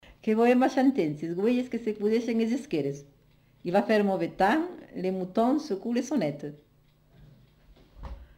Lieu : Cathervielle
Effectif : 1
Type de voix : voix de femme
Production du son : récité
Classification : proverbe-dicton